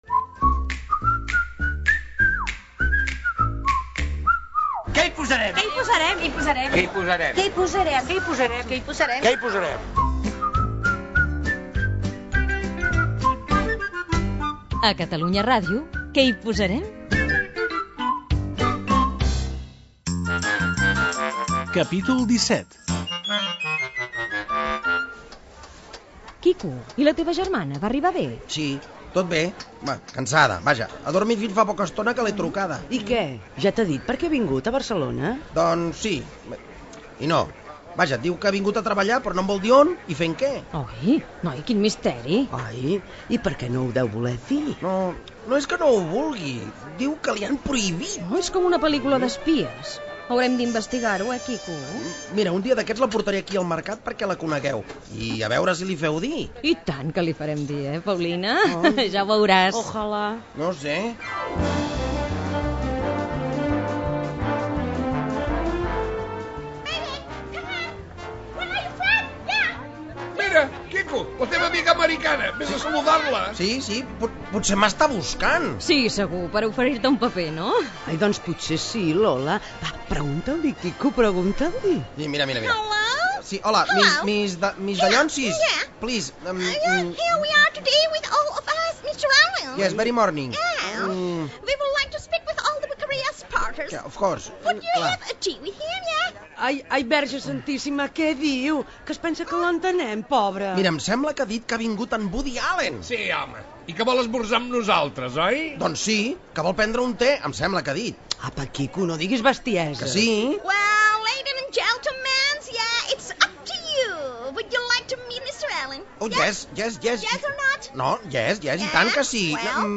Careta de la ficció i capítol en el qual Woody Allen arriba al mercat de la Boqueria de Barcelona, Careta de sortida amb els noms de l'equip.
Ficció